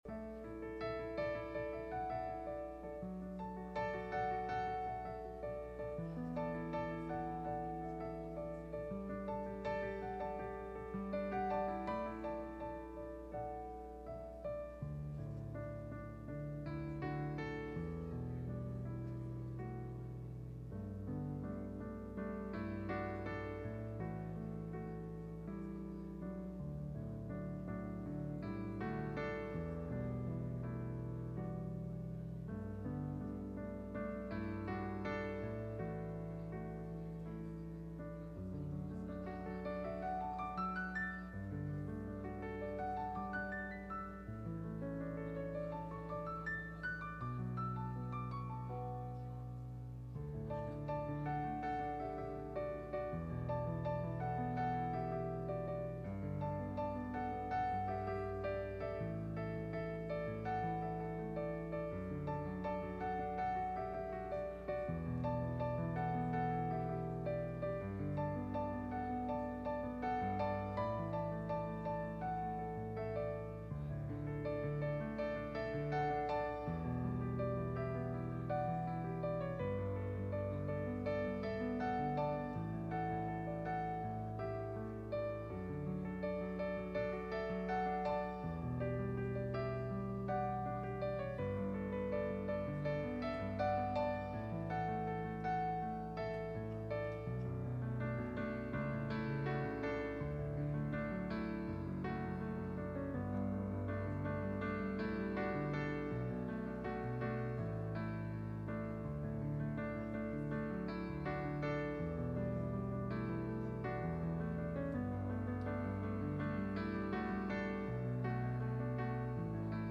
1 Samuel 1:1-11 Service Type: Midweek Meeting « Preaching The Four Anchors That Hold